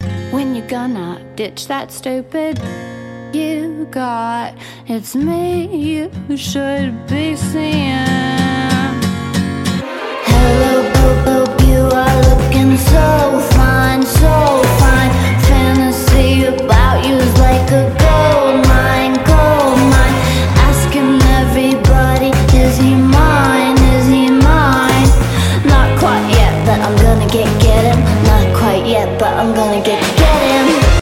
his cute little accent🥺🥺❤
this interview was adorable